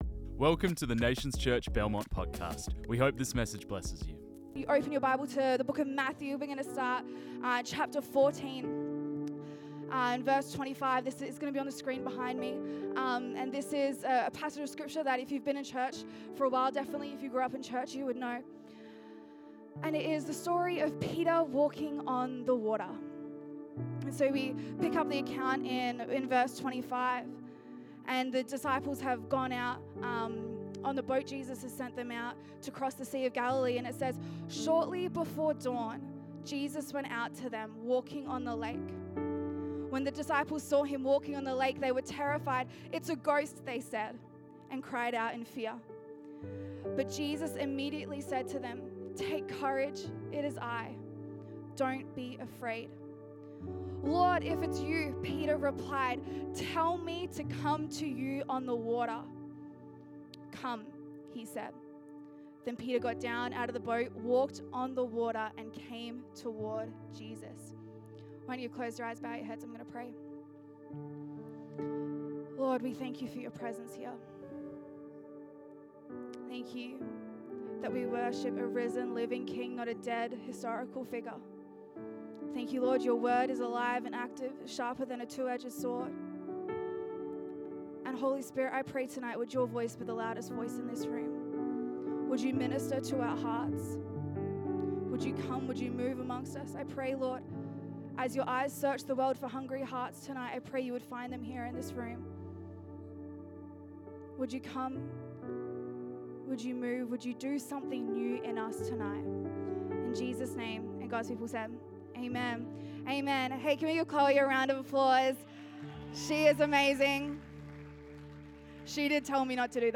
This message was preached on 04 May 2025.